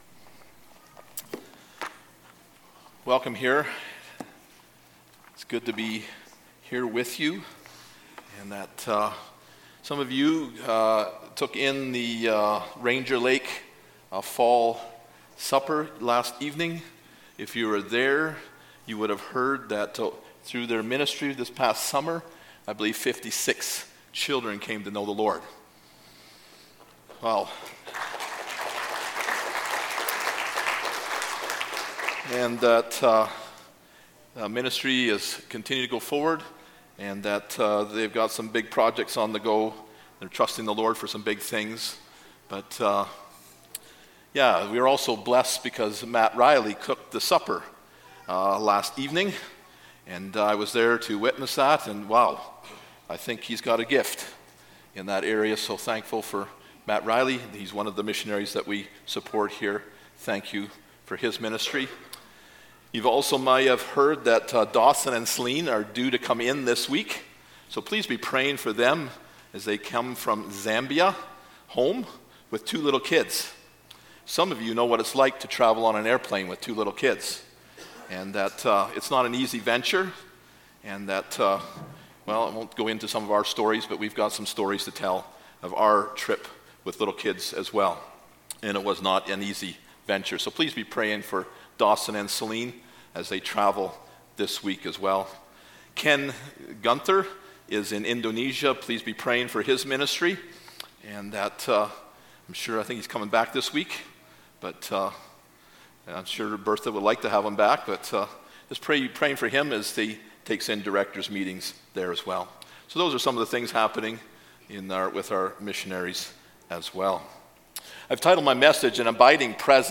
Service Type: Sunday Morning Topics: Holy Spirit